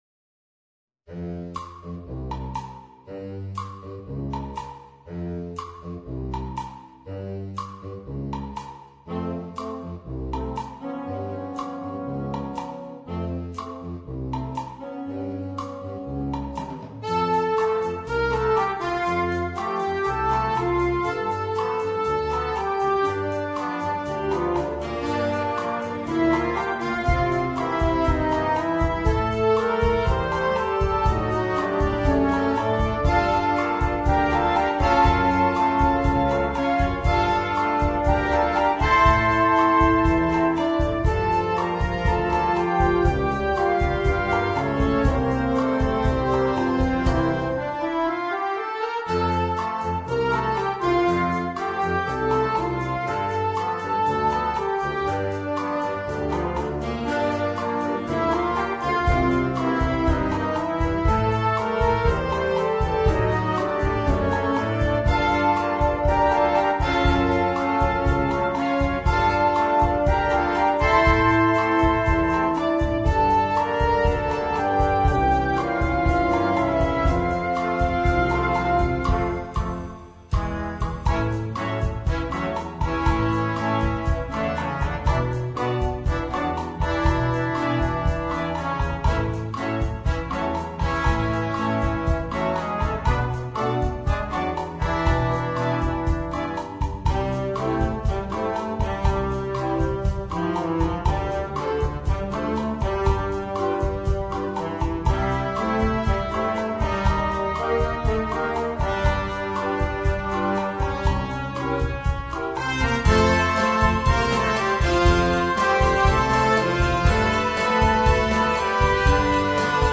for band